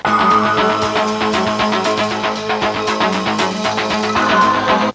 Exciting Intro Sound Effect
Download a high-quality exciting intro sound effect.
exciting-intro.mp3